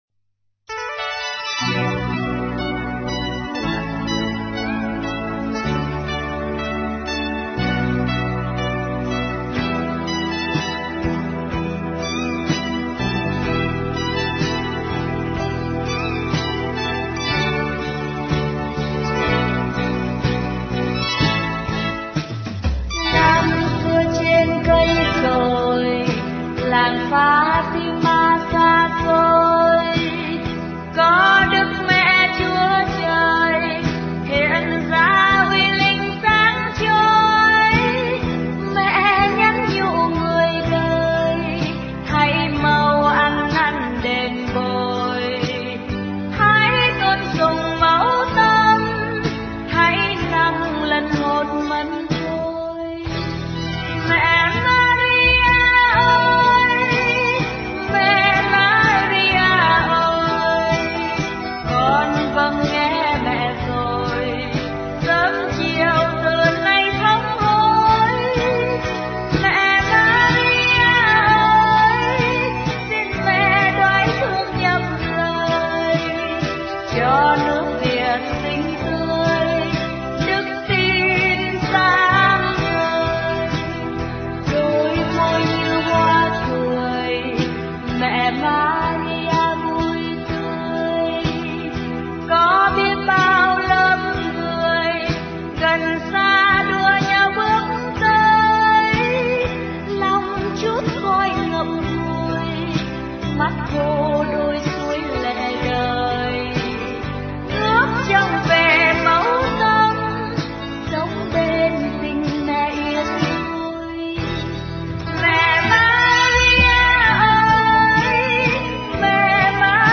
* Thể loại: Đức Mẹ